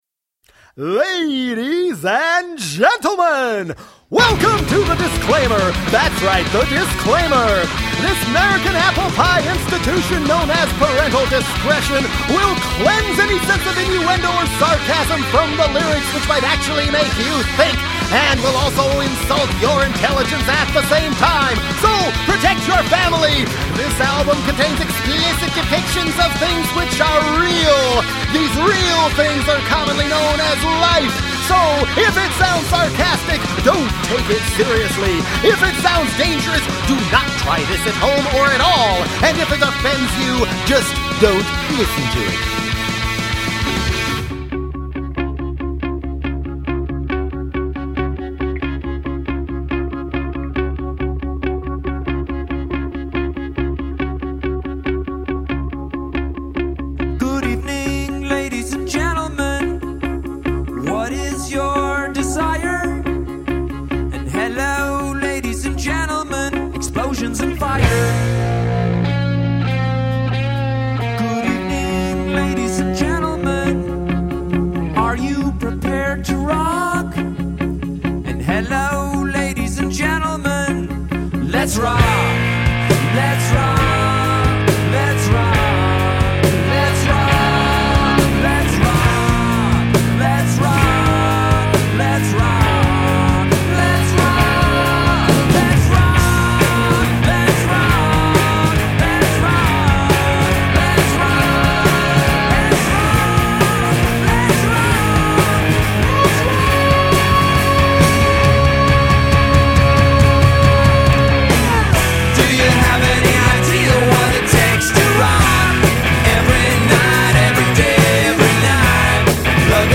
No tricks, no themes, no requests, just rock!!!!!